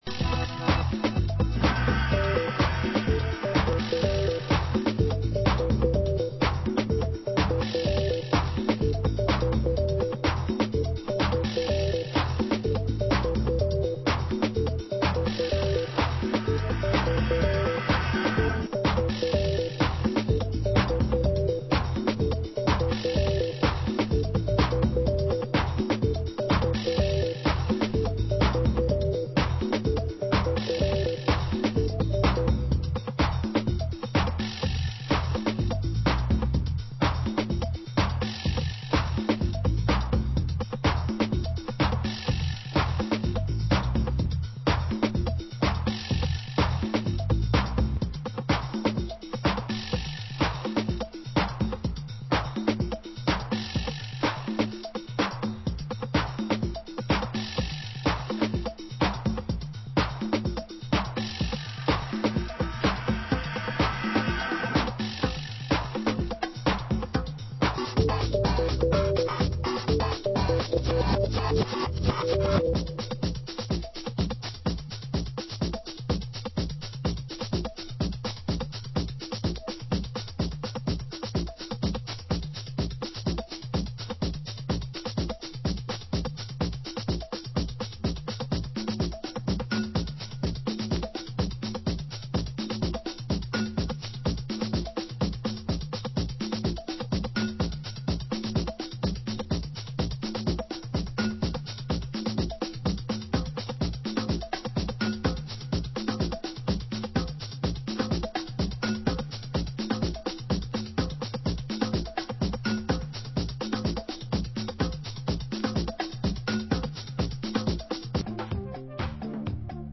Genre: Detroit Techno
Genre: Chicago House